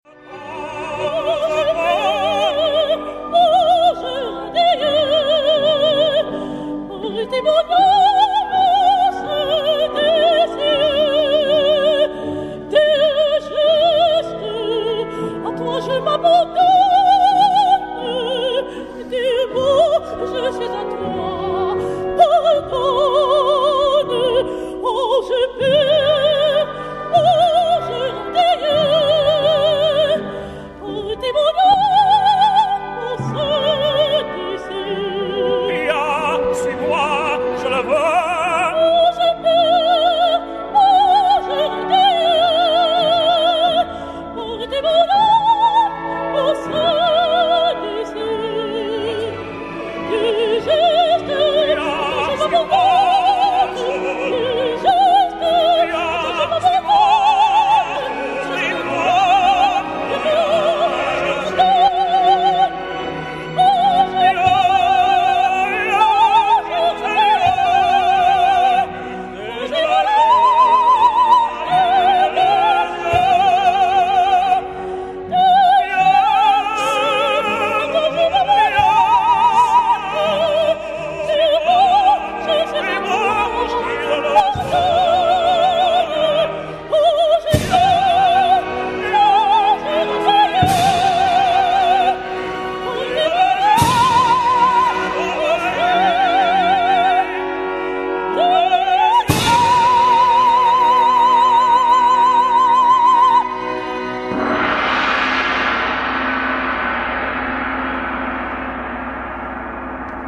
EXTRAITS D'ENREGISTREMENTS EN PUBLIC